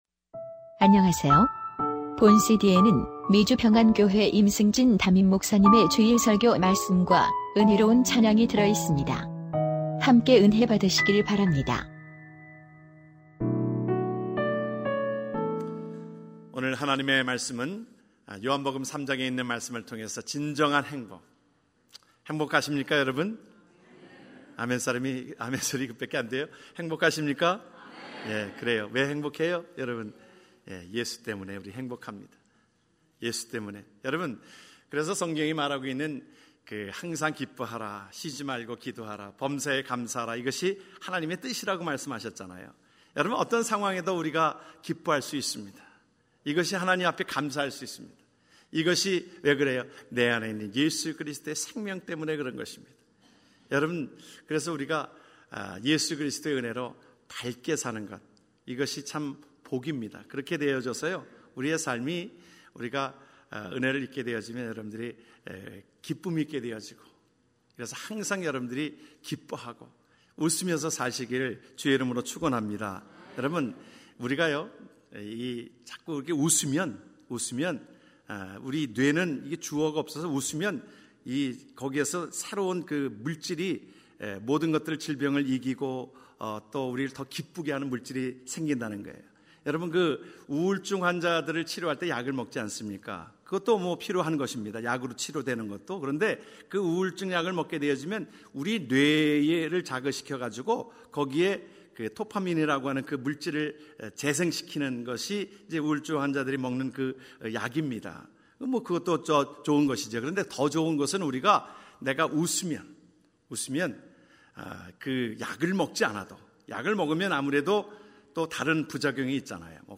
2015년 5월 3일 미주평안교회 주일설교말씀